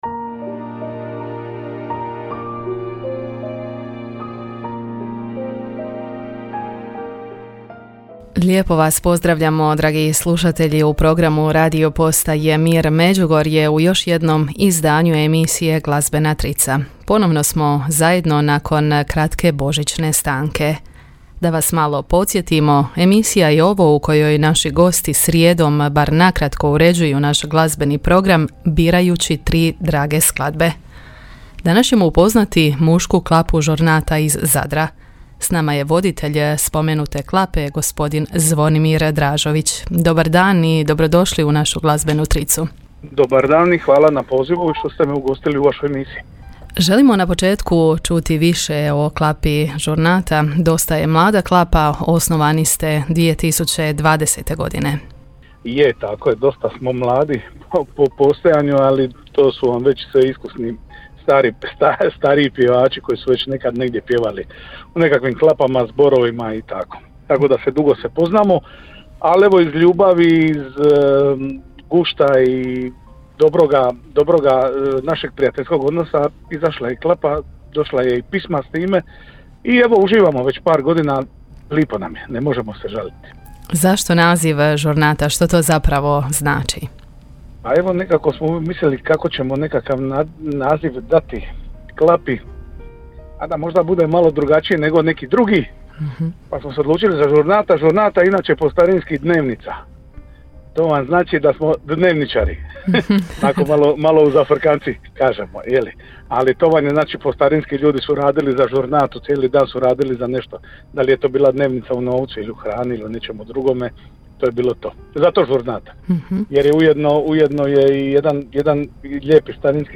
Klapa Žurnata njeguje tradicionalno klapsko a cappella pjevanje, kao i izvođenje skladbi sakralne naravi, sa ciljem nastupa na smotrama i susretima klapa, te klapskim festivalima.
Emisija je ovo u kojoj naši gosti na kratko uređuju naš glazbeni program, birajući tri drage skladbe.